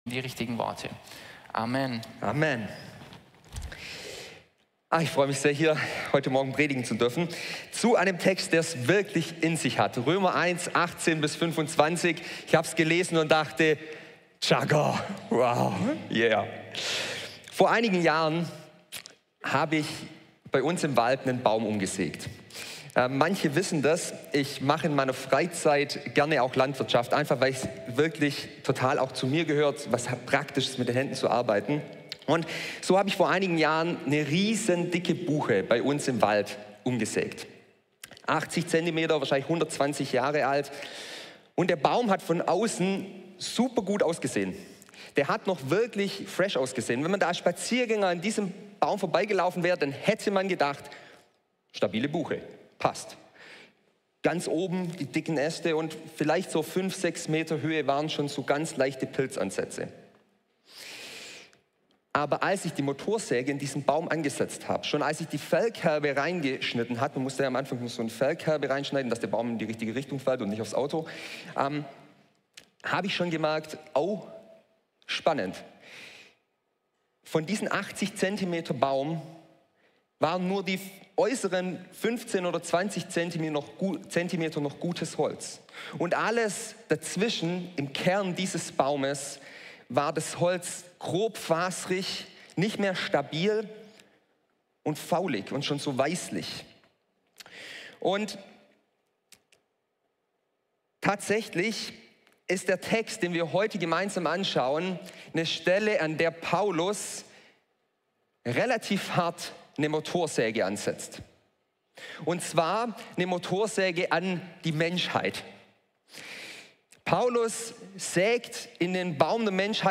Typ: Predigt